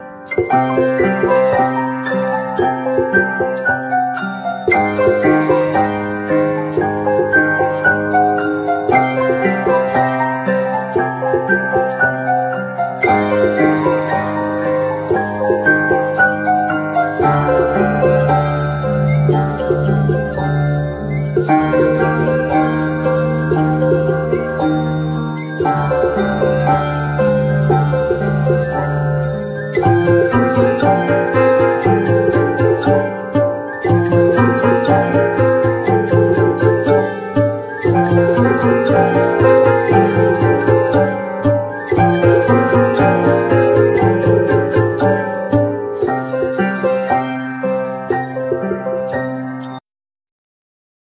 Ac.guitar,Organ,Piano,Bass,Melodica,Flute,Xylophne,Recorder